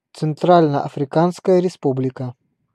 Ääntäminen
Translitterointi: Tsentralno-Afrikanskaja Respublika.